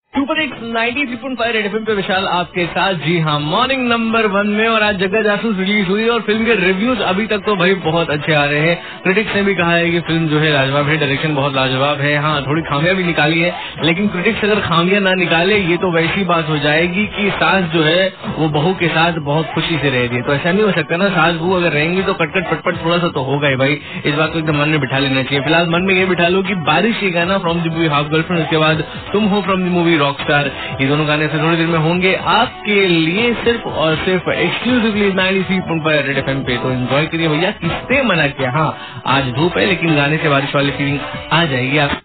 RJ TALKING ABOUT REVIEW OF JAGGA JASOOS